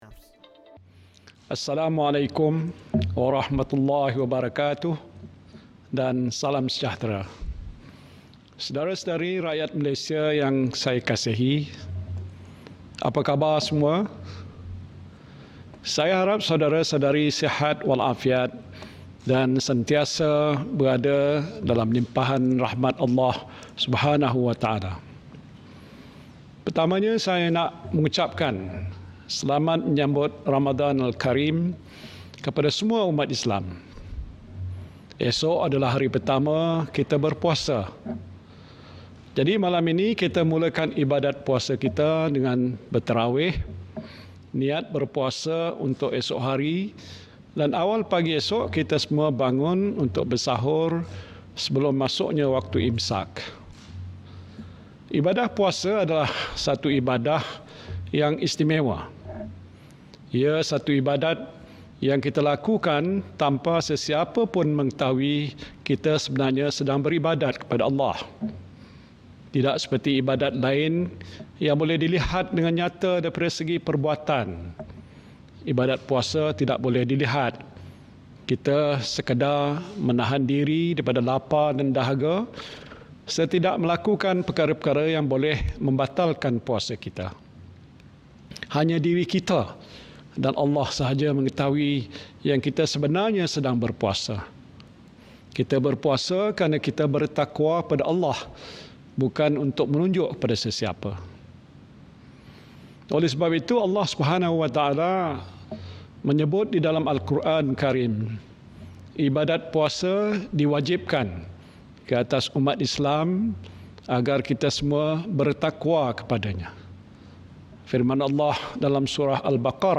Ikuti Perutusan Khas Ramadan oleh Perdana Menteri, Tan Sri Muhyiddin Yassin.